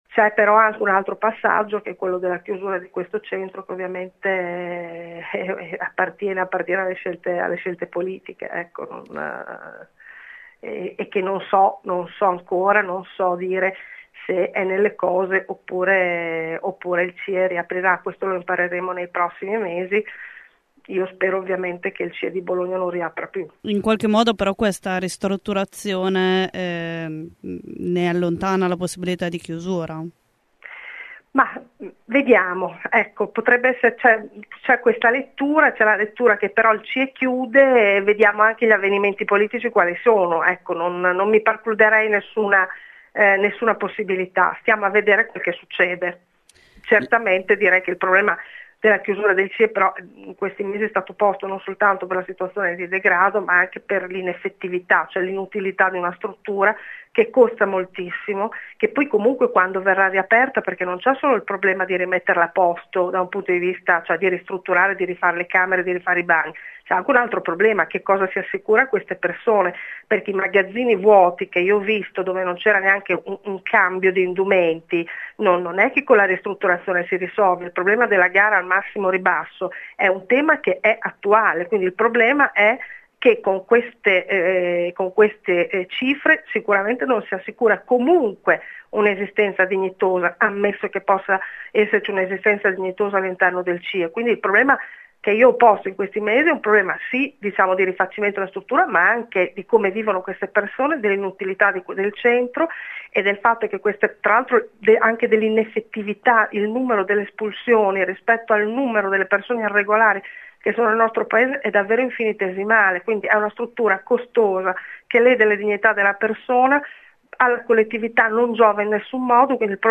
Ascolta la garante Desi Bruno
desi-bruno-cie-chiuso-post.mp3